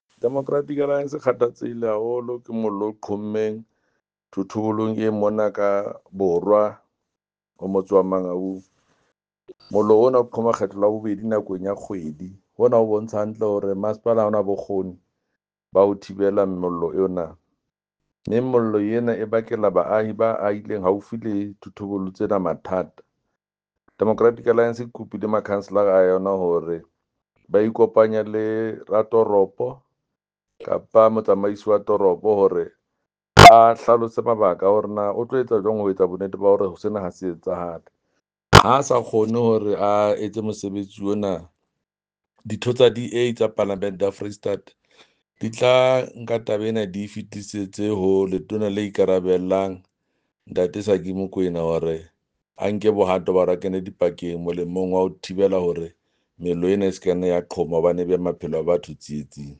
Sesotho soundbite by Jafta Mokoena MPL